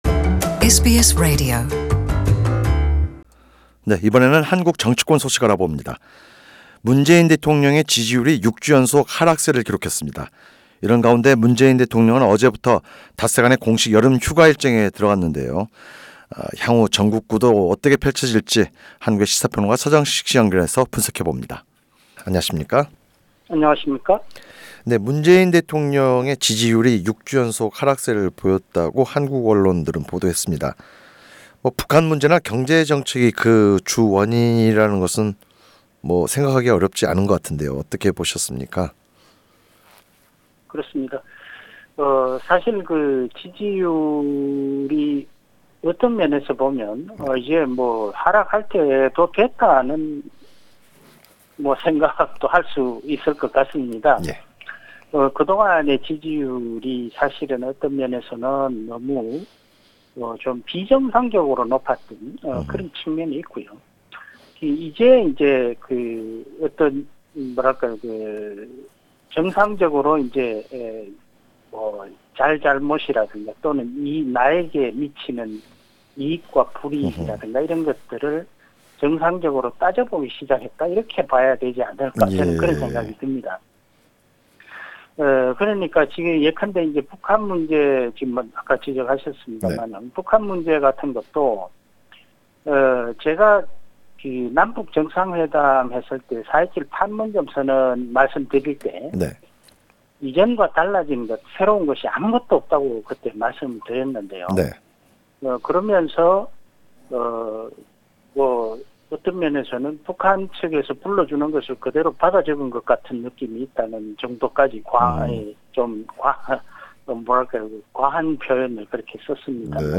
[자세한 내용은 오디오 뉴스로 접하시기 바랍니다.]